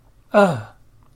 ə-individual.mp3